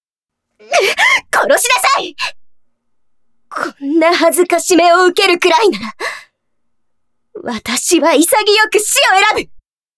ライトスキットボイス | PandoraPartyProject